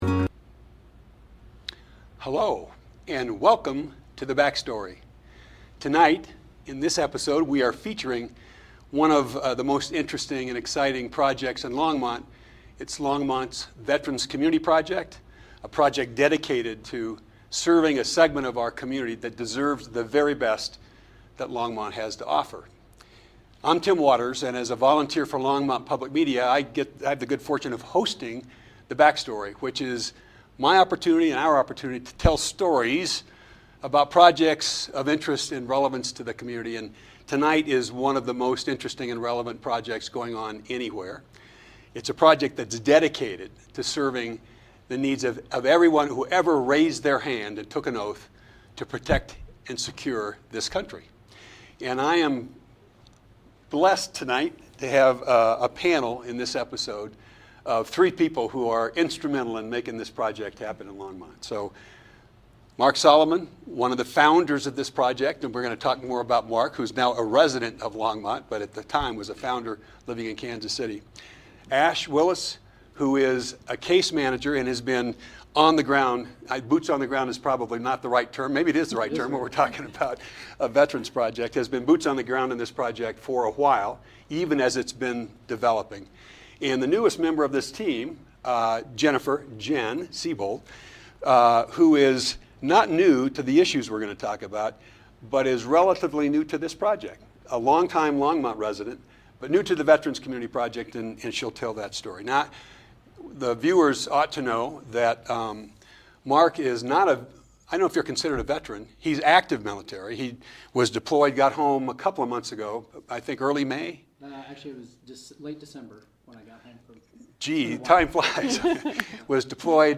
The Backstory on Veterans Community Project - Live at Longmont Public Media